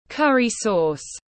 Nước sốt cà ri tiếng anh gọi là curry sauce, phiên âm tiếng anh đọc là /ˈkʌr.i sɔːs/
Curry sauce /ˈkʌr.i sɔːs/